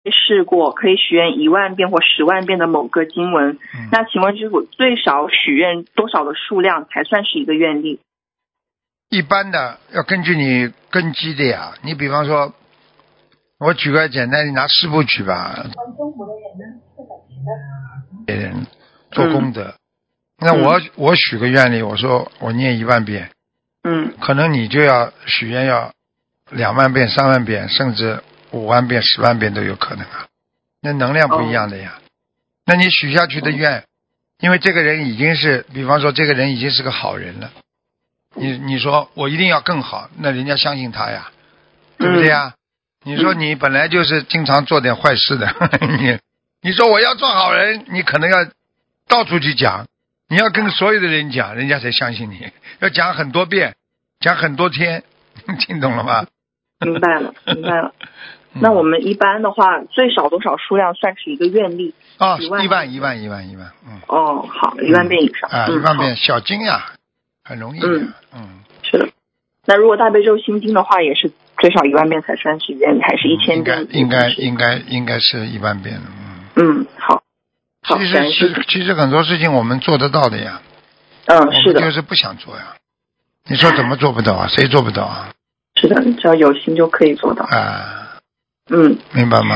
目录：☞ 2019年12月_剪辑电台节目录音_集锦